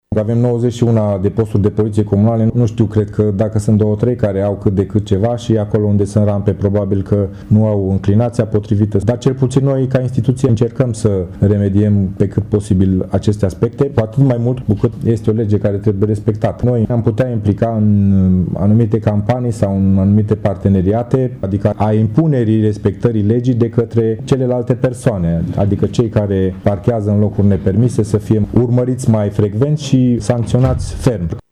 Aceasta este concluzia mesei rotunde de astăzi de la sediul Fundației Alpha Transilvană din Tîrgu-Mureș, la care au participat reprezentanți ai ONG-urilor care au ca obiect drepturile persoanelor cu dizabilități și reprezentanți ai administrației locale.